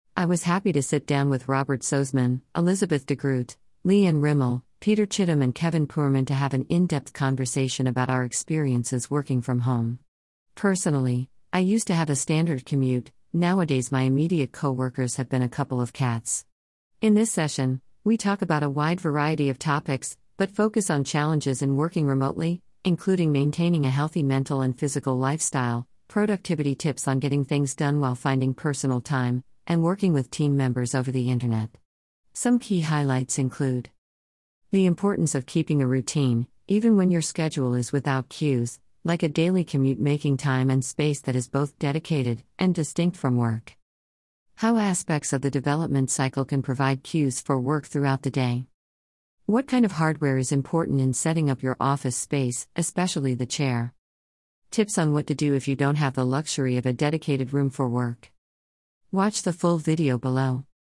Group Conversation on Remote Working | Salesforce Developers Blog